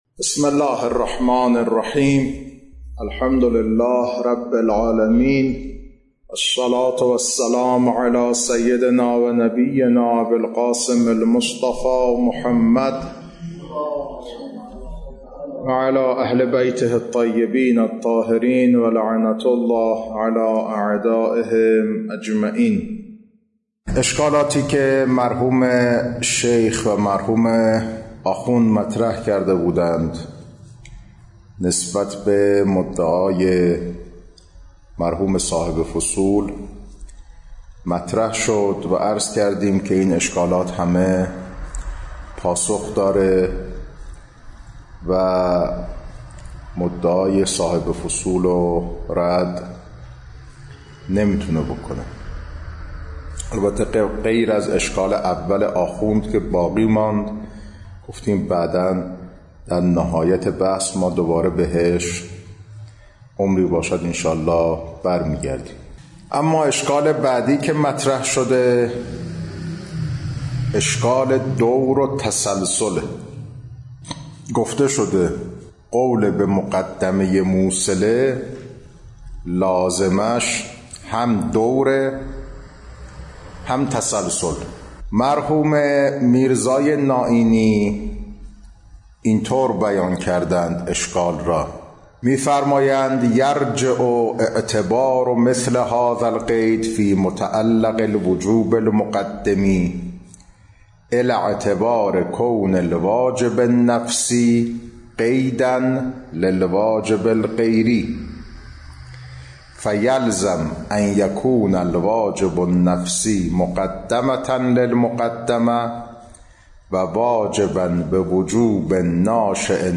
کلاس‌ها خارج اصول